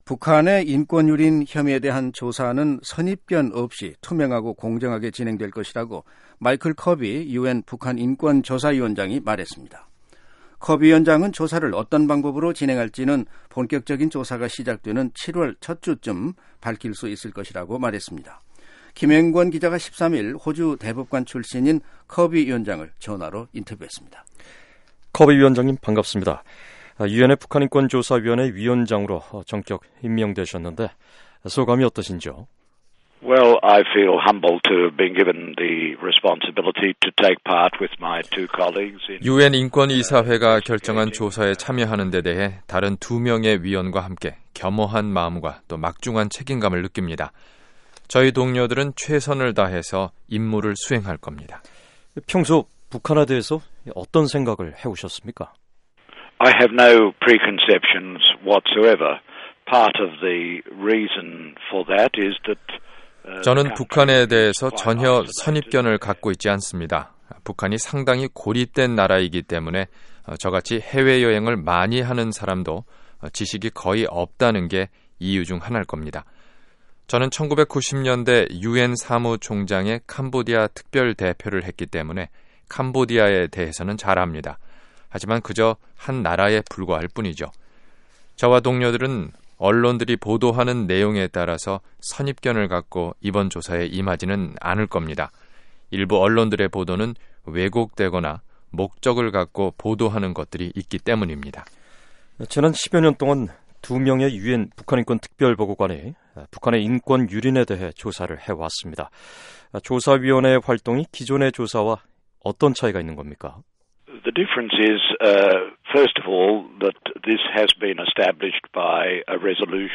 [단독 인터뷰] 마이클 커비 유엔 북한인권 조사위원장